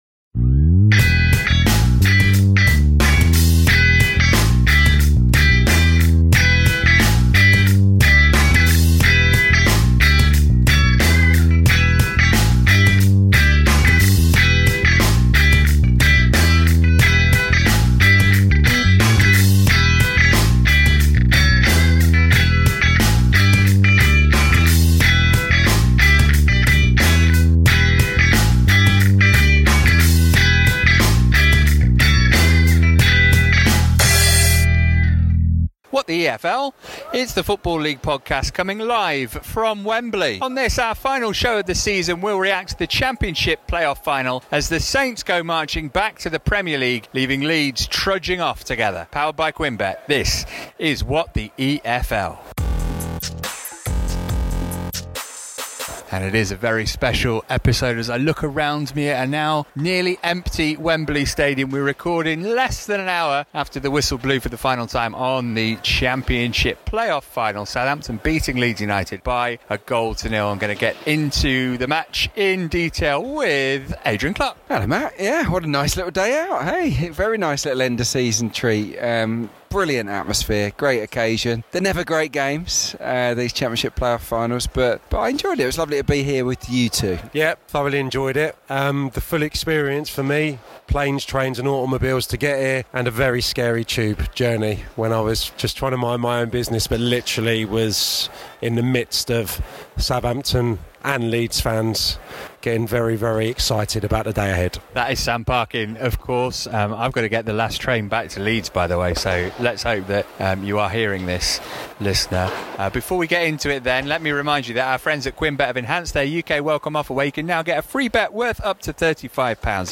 Live from Wembley!